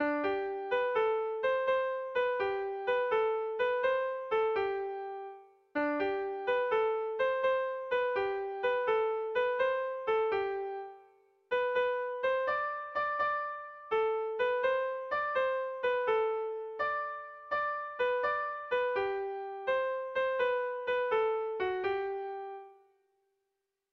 Air de bertsos - Voir fiche   Pour savoir plus sur cette section
Zortziko txikia (hg) / Lau puntuko txikia (ip)
AABD